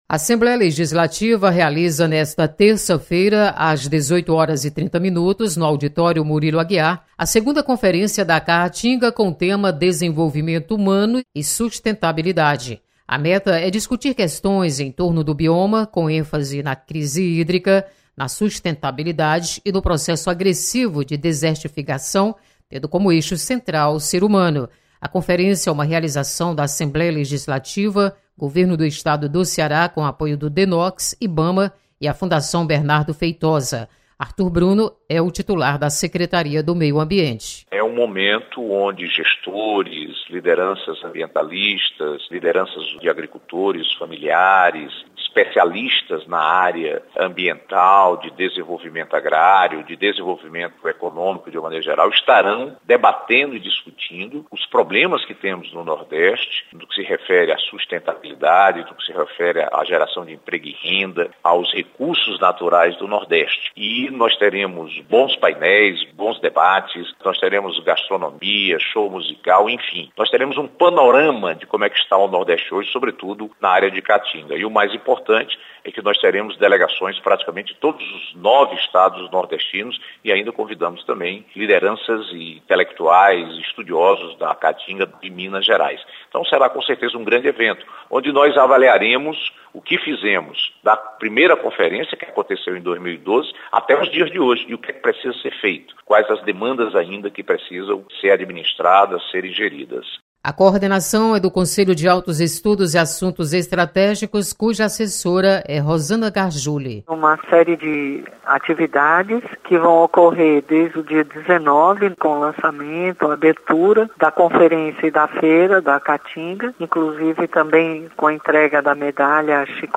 Segunda Conferência da Caatinga acontece esta terça-feira. Repórter